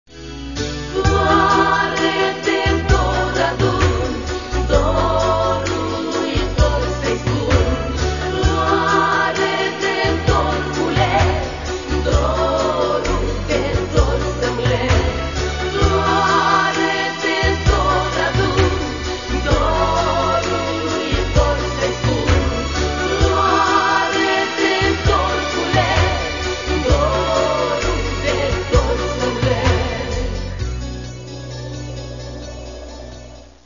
Каталог -> Естрада -> Співачки